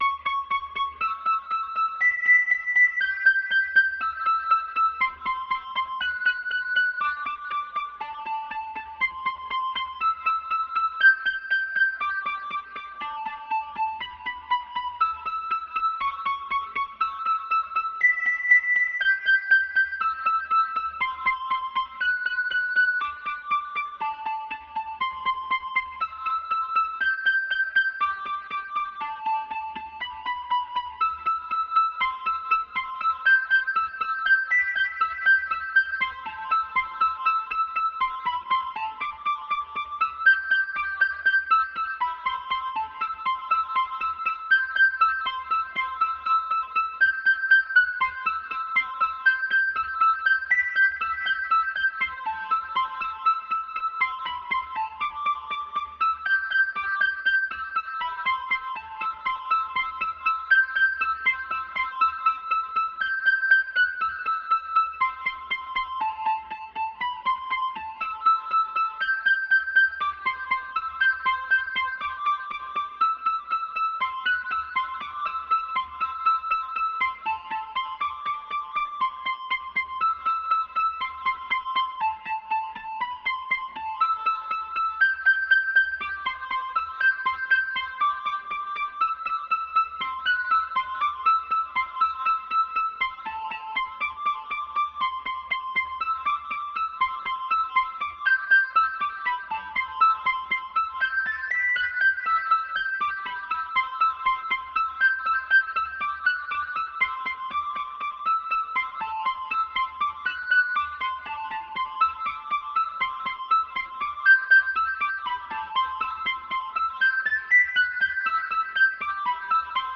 It uses the former Cakewalk Digital Audio Workstation software SONAR Platinum. The software synthesizer utilized was Universal Audio Waterfall Hammond B3 Organ emulator with Lesley Type 147 amplifier and rotating speaker enclosure.